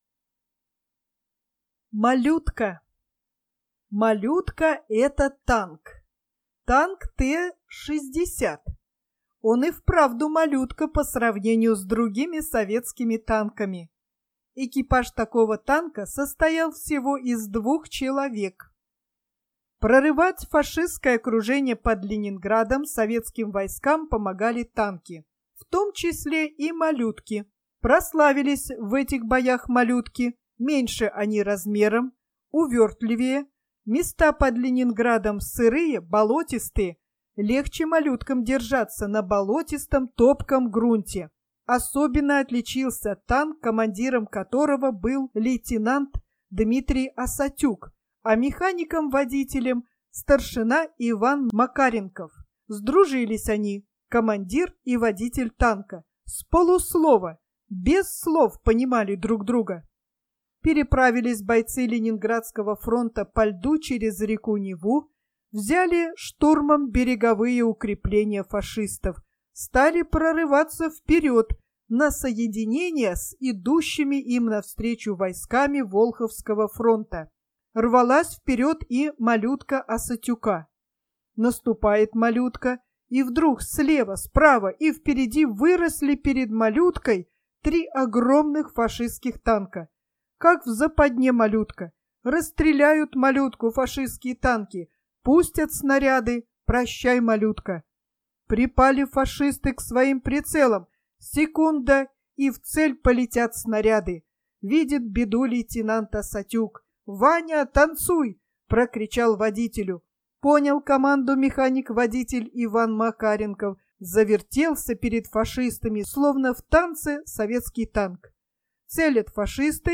Аудио рассказ детского писателя Сергея Петровича Алексеева "Малютка" о танке Т-60, из книги "Рассказы о Великой Отечественной войне".